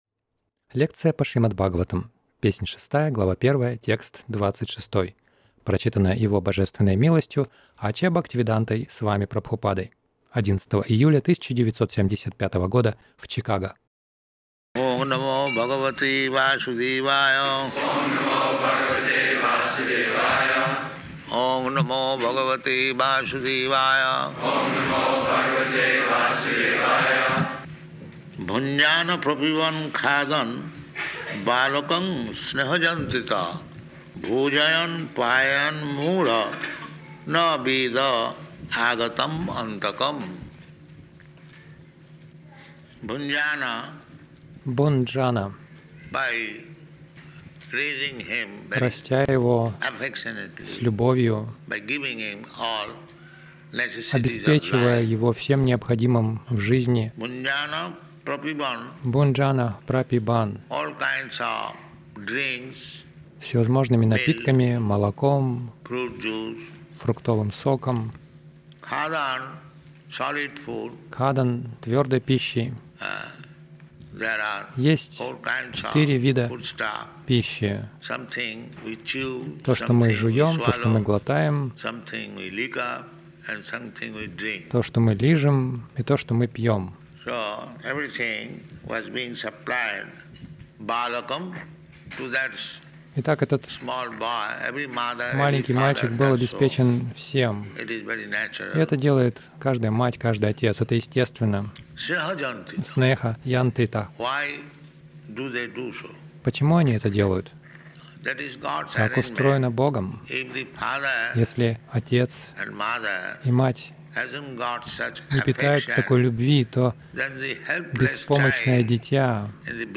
Милость Прабхупады Аудиолекции и книги 11.07.1975 Шримад Бхагаватам | Чикаго ШБ 06.01.26 — Истинное благо — вернуться домой к Богу Загрузка...